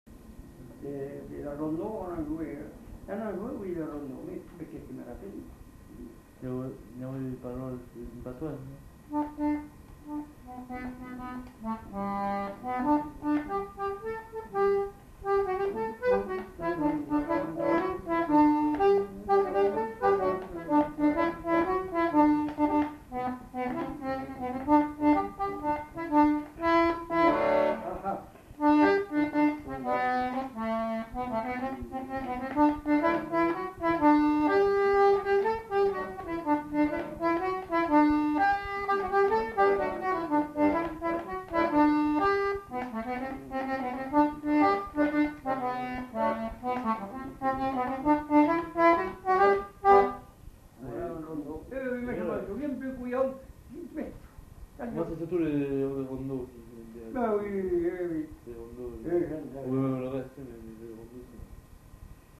Répertoire d'airs à danser du Marmandais à l'accordéon diatonique
enquêtes sonores
Rondeau